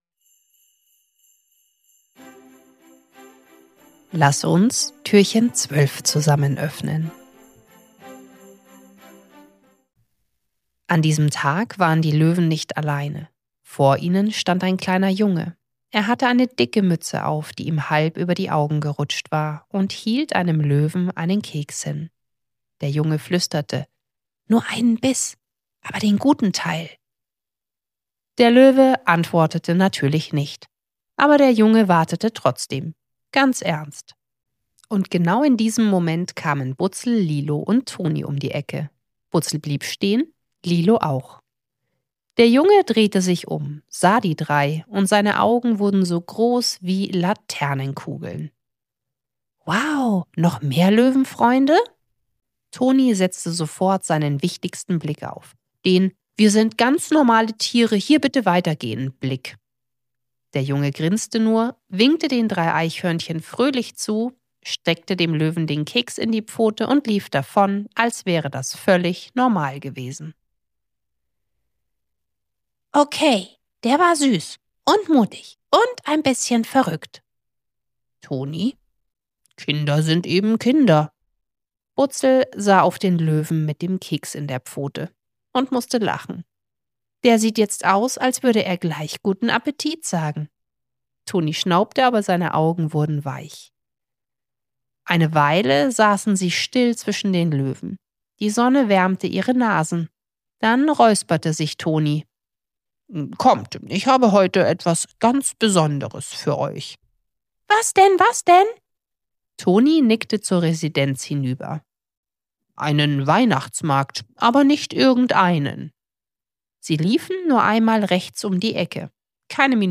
Türchen – Butzel und der Baum voller Wünsche ~ Butzels Adventskalender – 24 Hörgeschichten voller Herz & kleiner Wunder Podcast